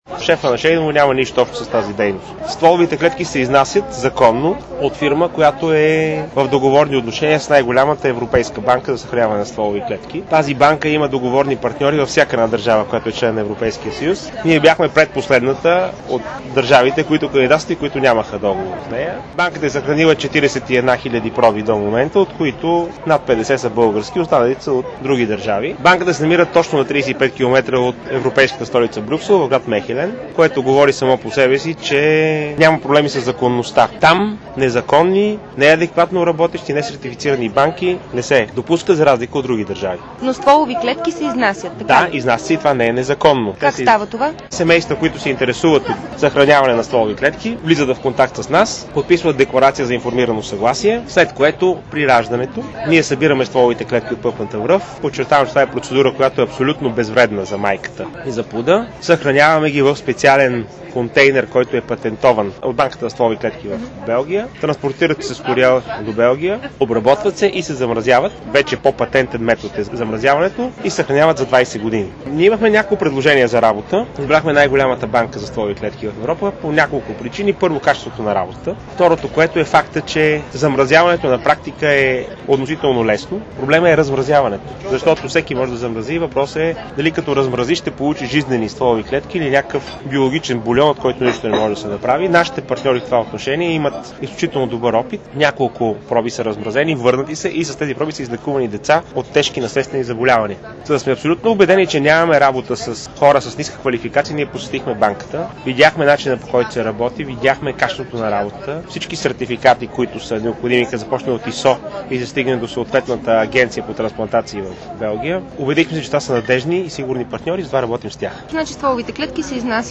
DarikNews audio: Интервю